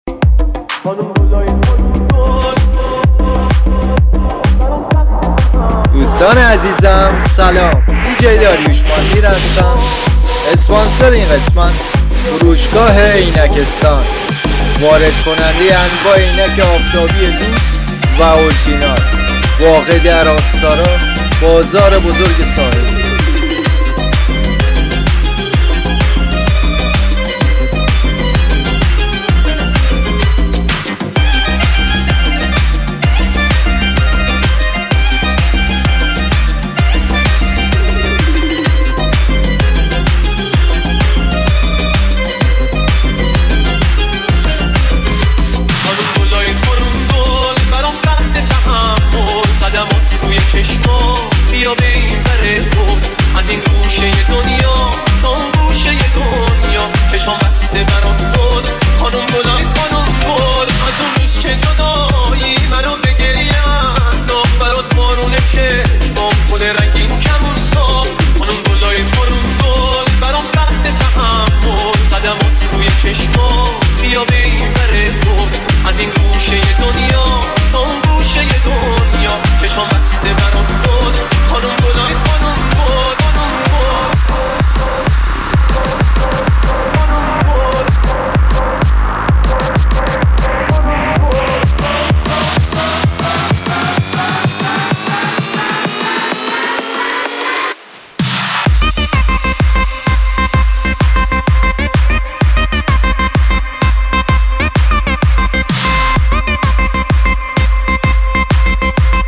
بمب شادی
یه کار شاد و پرانرژی مخصوص طرفدارای موزیک‌های شاد و رقصی